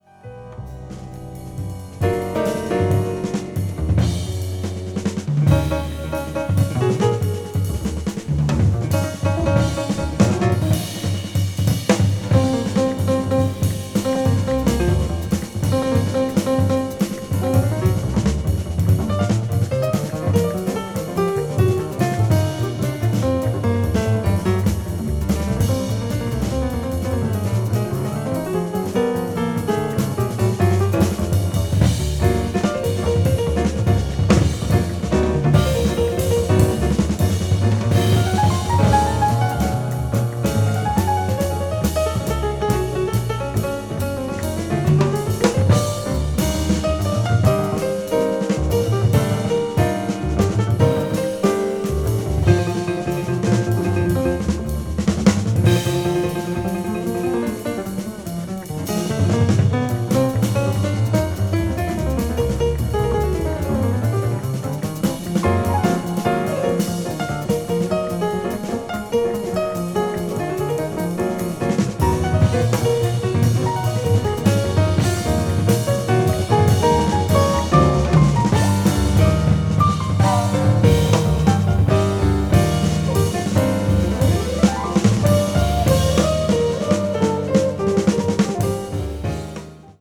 media : EX+/EX+(わずかにチリノイズが入る箇所あり)
contemporary jazz   deep jazz   experimental jazz